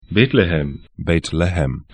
Aussprache
Bethlehem 'be:tlehɛm Bayt Lahm bɛĭt'lɛhəm ar Stadt / town 31°43'N, 35°12'E